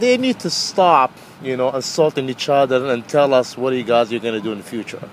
A MAN OUTSIDE A SHOPPING CENTER IN SOMERVILLE, MASS MONDAY NIGHT SAYS REPUBLICAN CANDIDATES NEED TO STOP FIGHTING AMONG EACH OTHER AND SAY WHAT THEY ARE GOING TO DO FOR THE FUTURE.
MAN-THEY-NEED-TO-STOP-ASSAULTING-EACH-OTHER-AND-TELL-US-WHAT-THEY-ARE-GOING-TO-DO-IN-THE-FUTURE.mp3